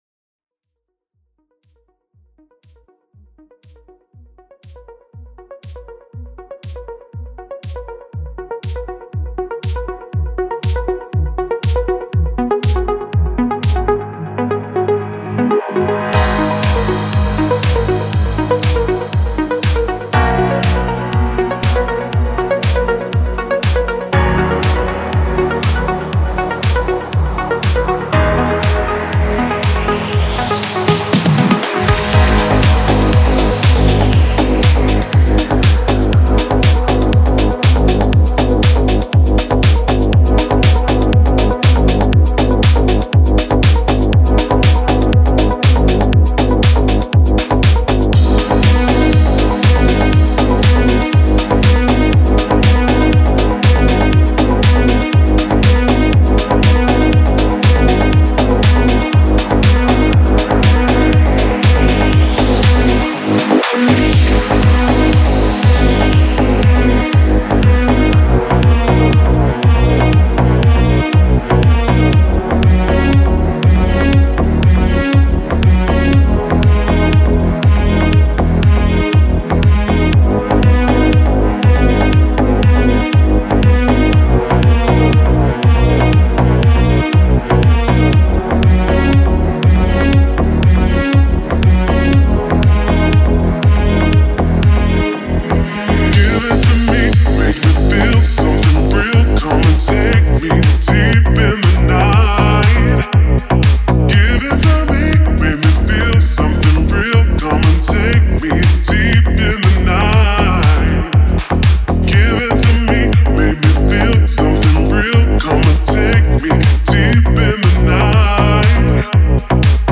Лёгкий и приятный))